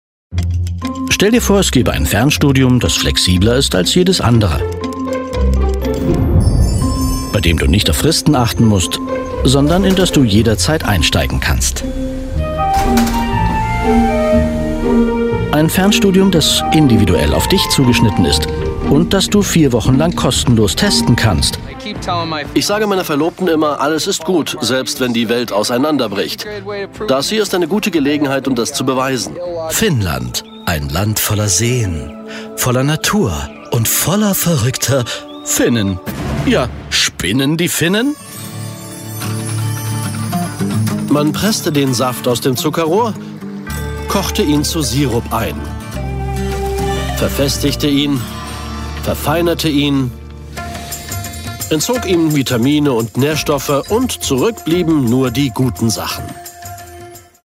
Und das je nach Charakter mal seriös, mal lustig, mal mystisch oder mal mit einem Augenzwinkern 😉